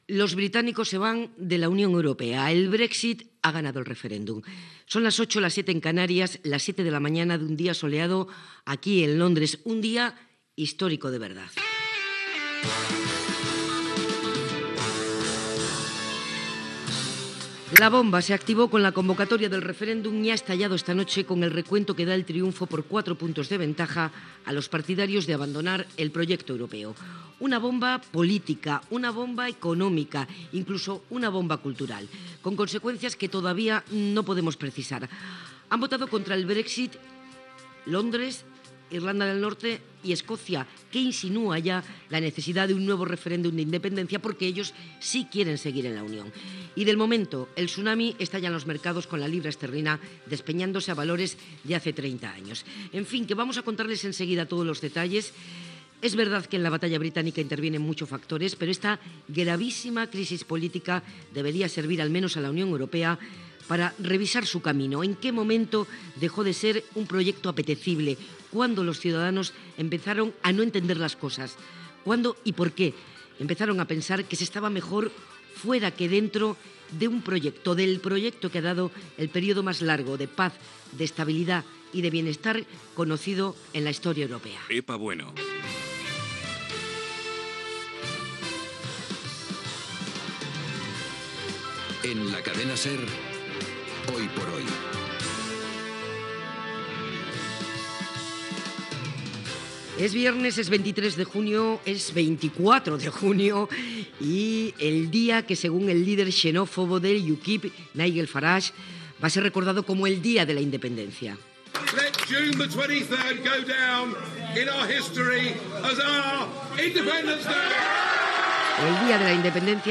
Resultat del referèndum sobre la qüestió al Regne Unit, celebrat el dia anterior. Indicatiu del programa.
Info-entreteniment